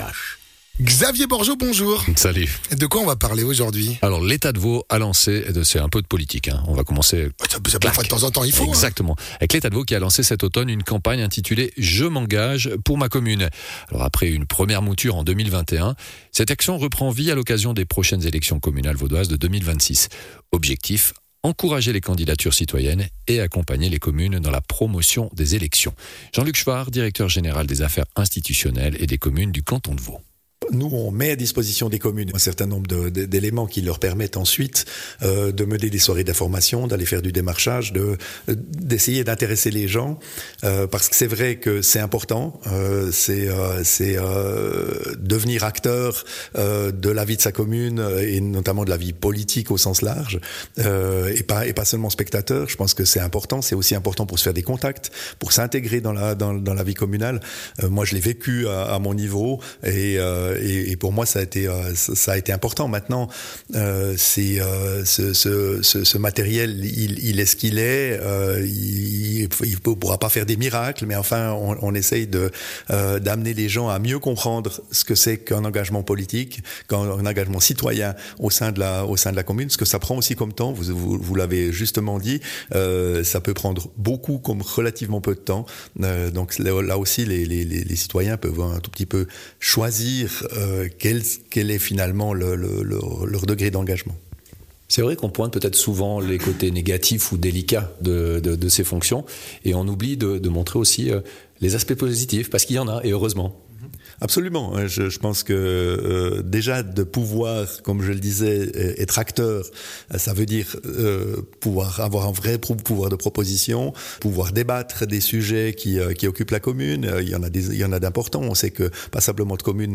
Intervenant(e) : Jean-Luc Schwaar, Directeur général des affaires institutionnelles et des communes, VD